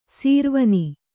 ♪ sīrvani